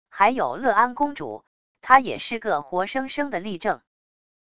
还有乐安公主……她也是个活生生的例证wav音频生成系统WAV Audio Player